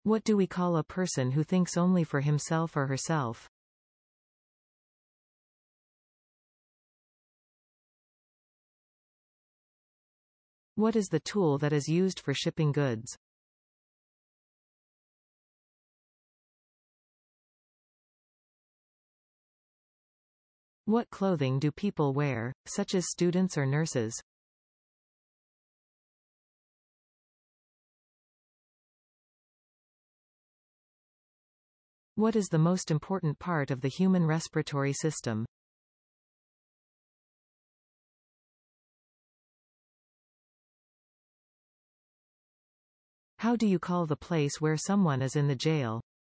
You will hear a question.